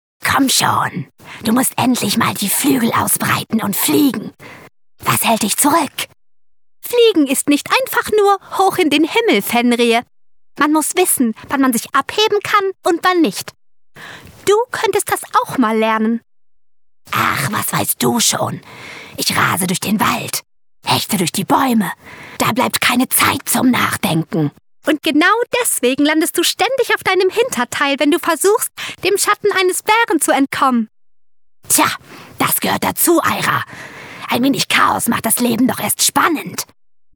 Sprecherin, Werbesprecherin